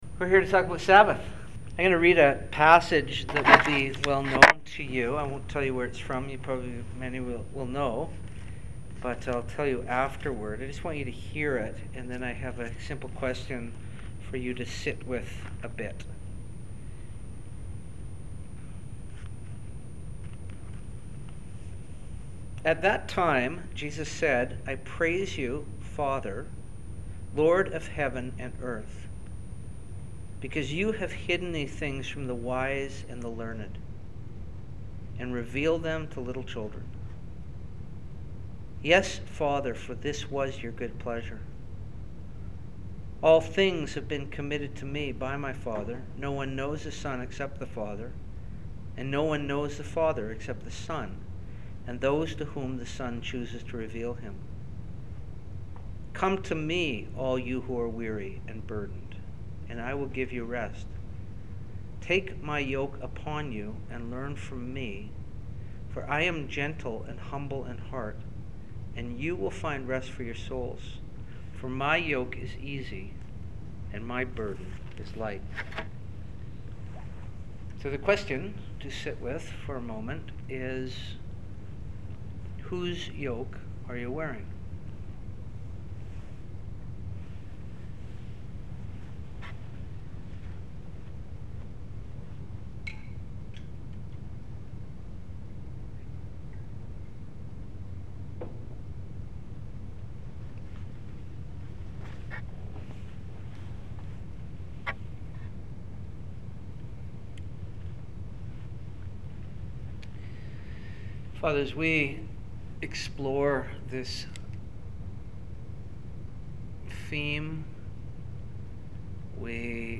All our busyness is not making us more fruitful – indeed, sometimes the opposite. This workshop explores why Sabbath-keeping is a foundational spiritual practice and will look at ways to receive the day.